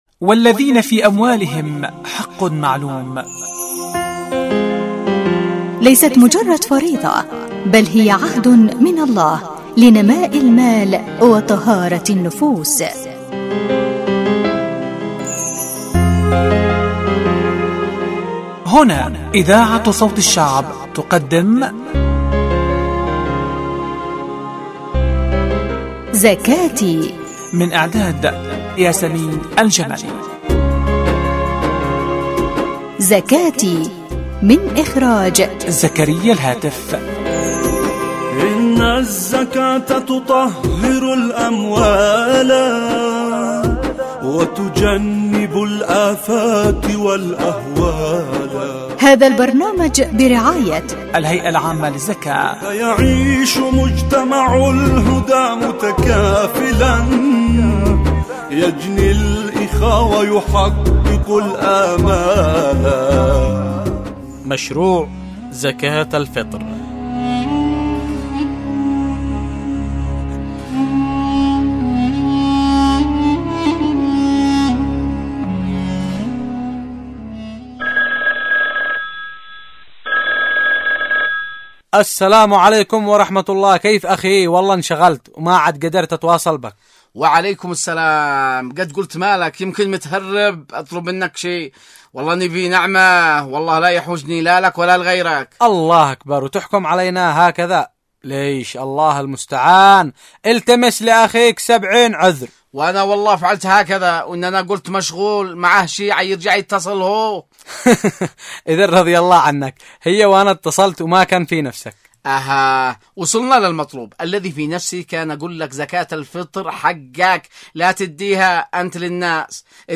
البرامج الحوارية